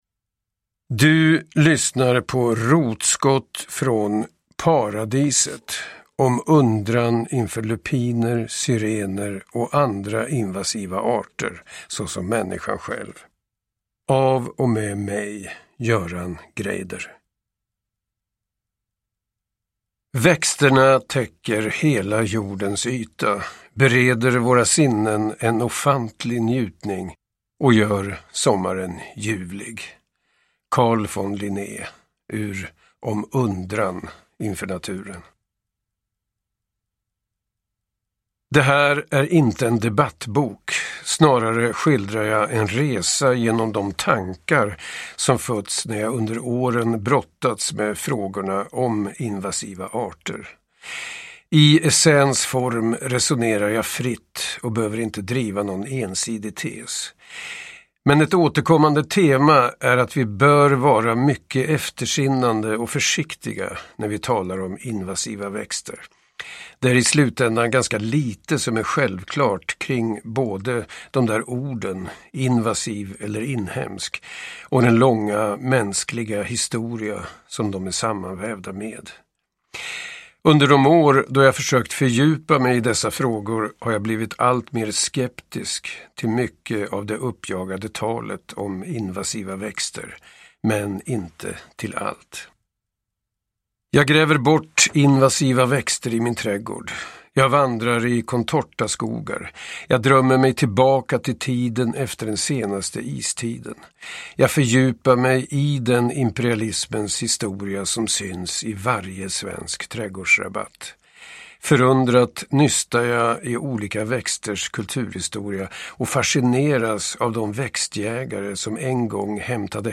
Rotskott från paradiset : om undran inför lupiner, syrener och andra invasiva arter, såsom människan själv – Ljudbok
Uppläsare: Göran Greider